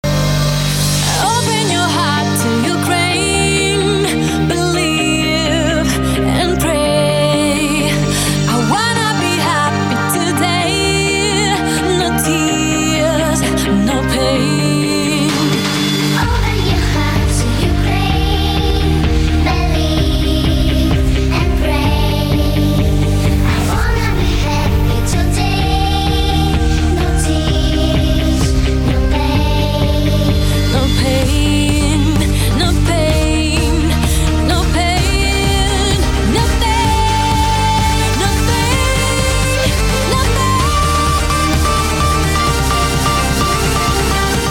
• Качество: 320, Stereo
женский вокал
мелодичные
dance
спокойные
club
красивый женский голос
звонкие